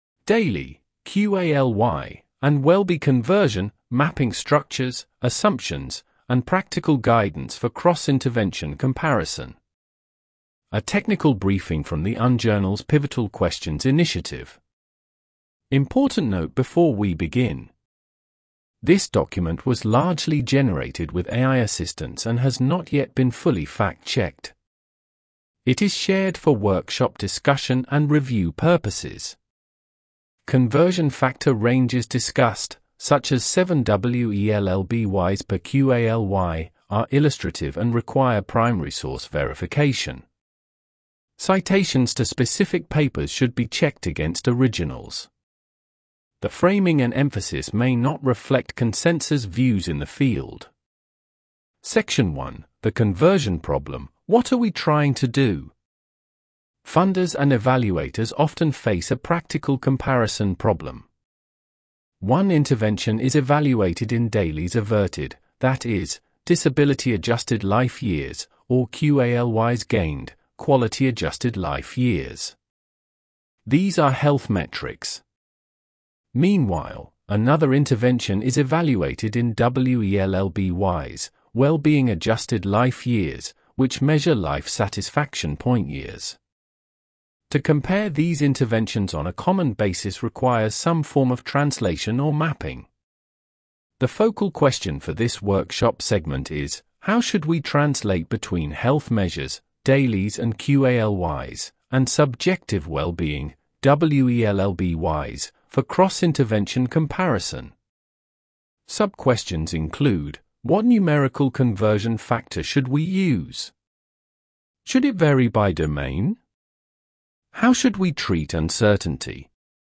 Listen to an audio narration of this page (British academic voice):
Generated with Microsoft Edge TTS (en-GB-RyanNeural)